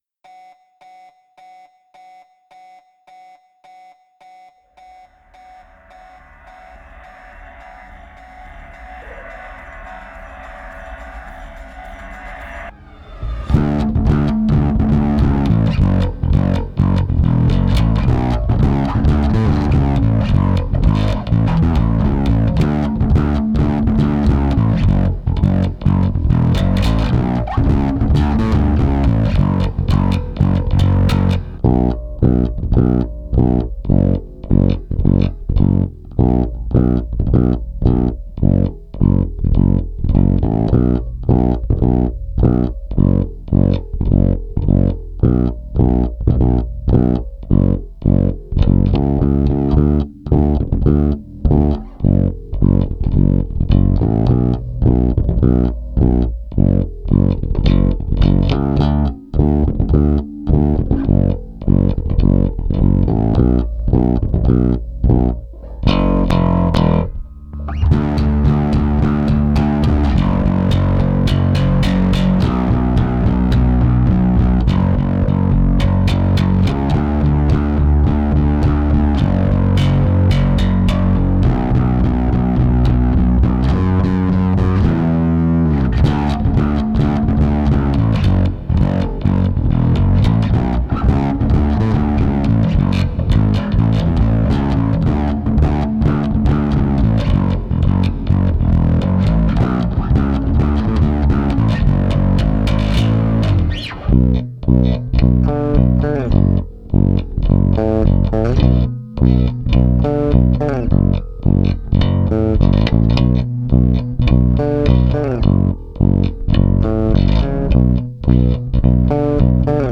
bass only